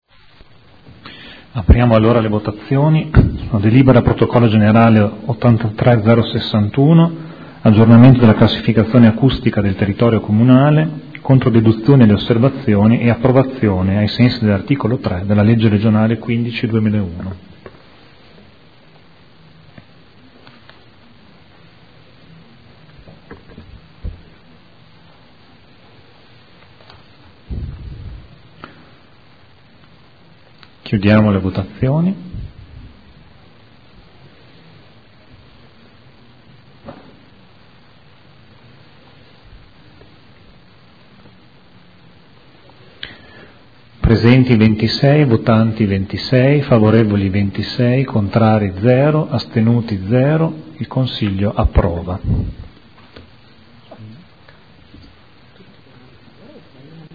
Seduta del 2 luglio. Proposta di deliberazione: Aggiornamento della classificazione acustica del territorio comunale – Controdeduzioni alle osservazioni e approvazione ai sensi dell’art. 3 della L.R. 15/2001.